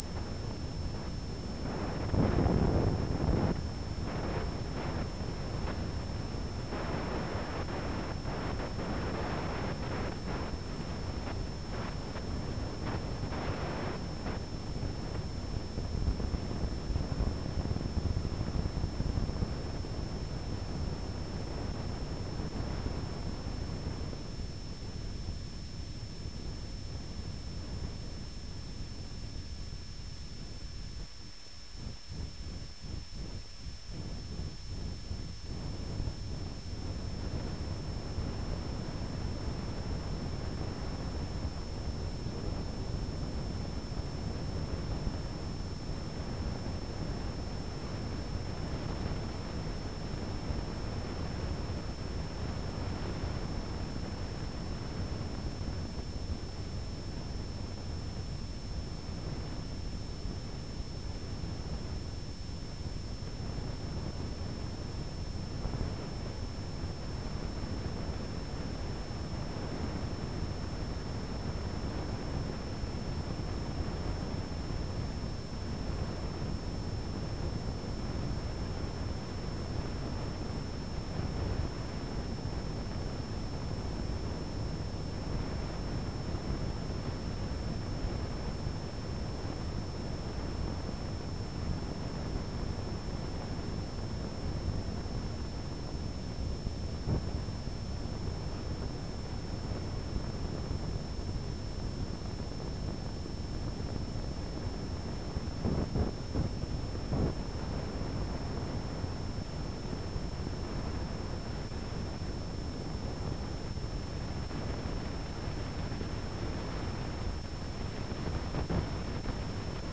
запись фликкер шума (wav-файл, 18 708 Кб) [размещено на сайте 19.11.2013]